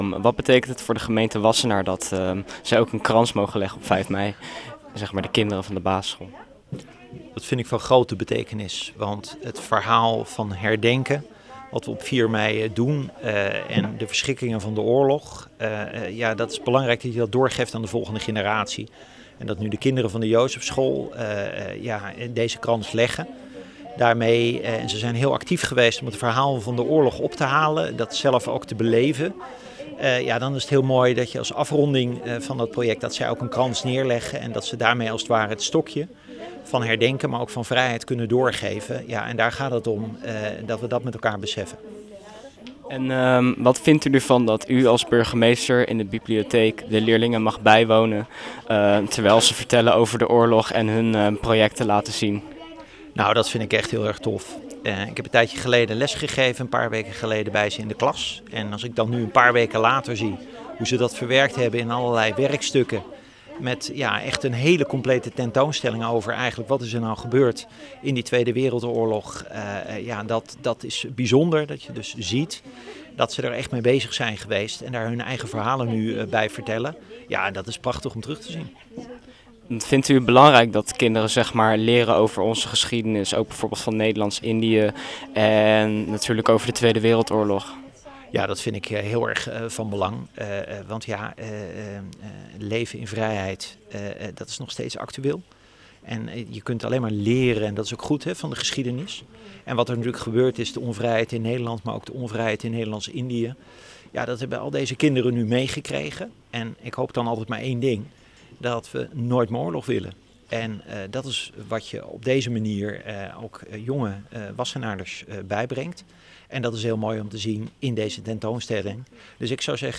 Interview-burgemeester-wassenaar_mixdown-end.wav